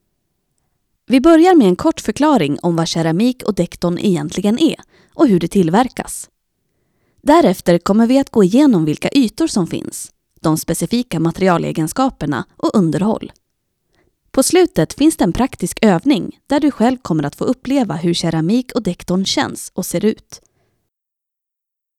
Female
Character, Confident, Natural, Warm, Witty, Versatile, Approachable, Conversational, Corporate, Energetic, Funny, Young
Northern Swedish (native). General Swedish.
Corporate.mp3
Microphone: Neumann TLM 103
Audio equipment: Voice booth